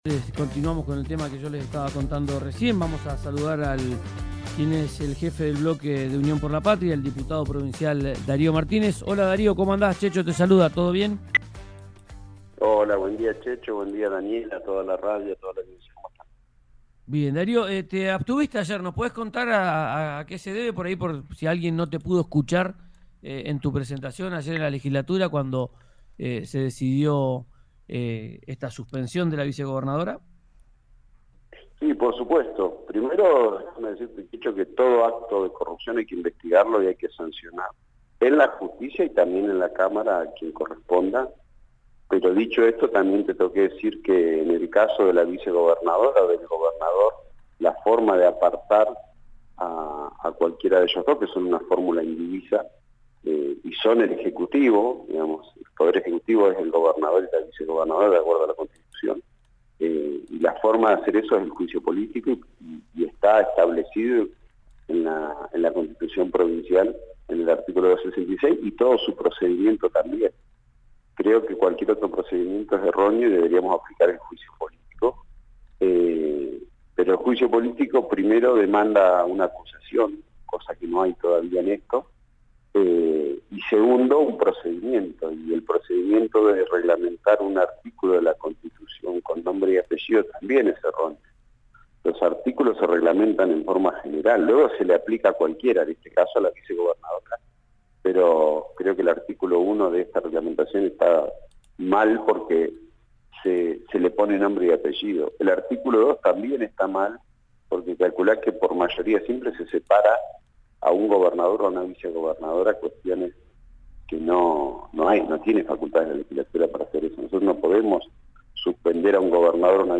El diputado Darío Martínez habló con RÍO NEGRO RADIO tras la suspensión a Gloria Ruiz.
Escuchá a Darío Martínez, diputado provincial de Unión por la Patria, en RÍO NEGRO RADIO: